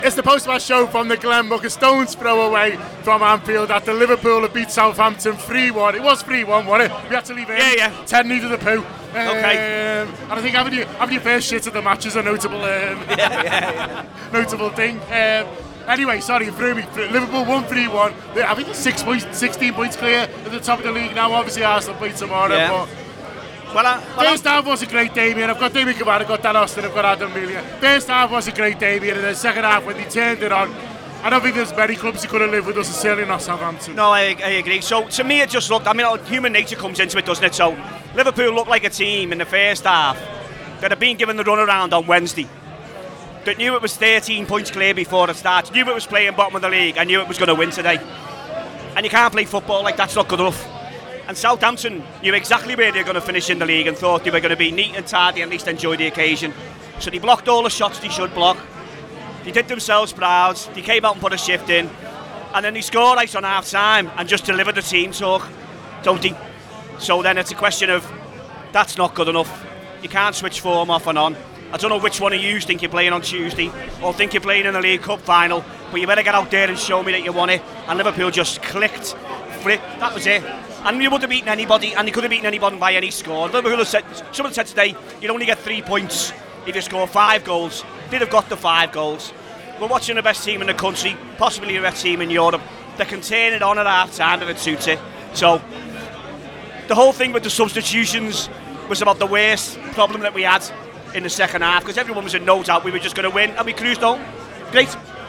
Below is a clip from the show – subscribe to The Anfield Wrap for more reaction to Liverpool 3 Southampton 1